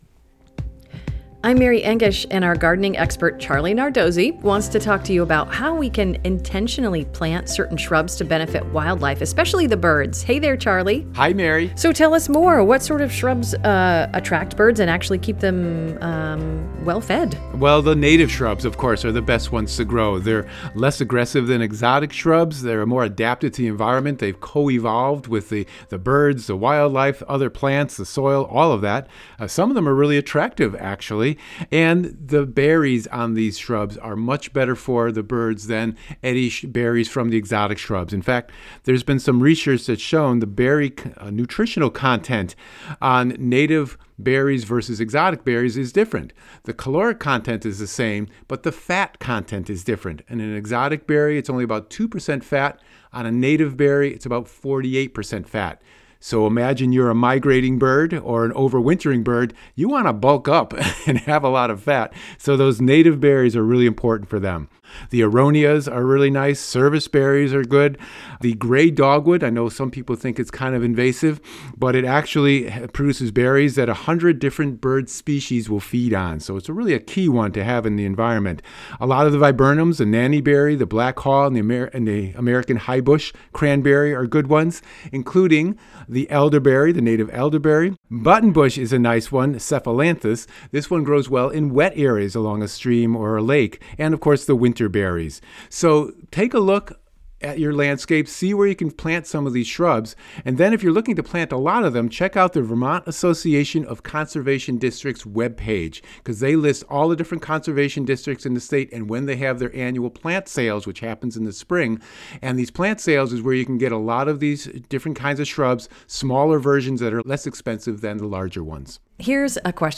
a conversation about gardening, and to answer your questions about what you're seeing in the natural world.